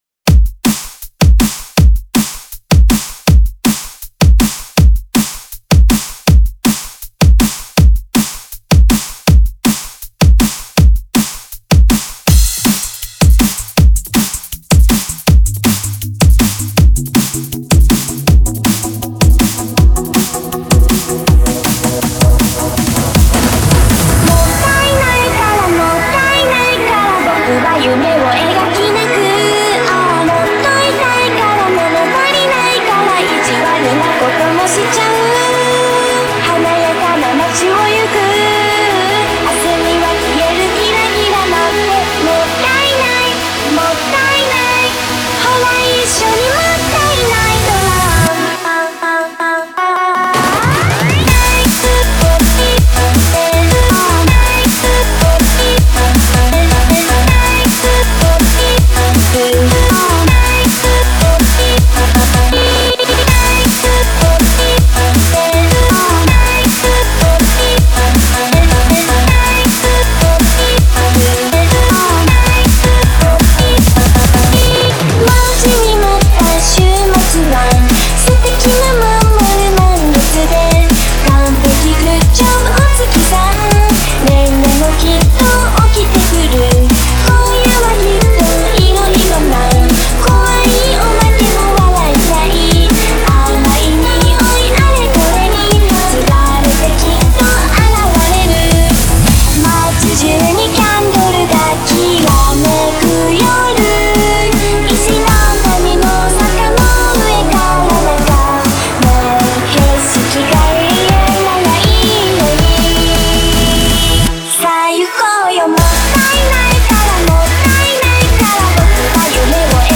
Genre(s): 80’s